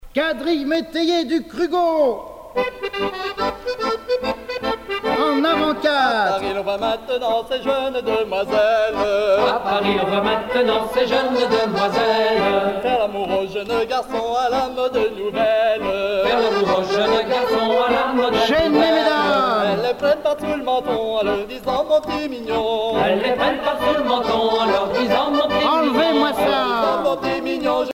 danse : quadrille : avant-quatre
Pièce musicale éditée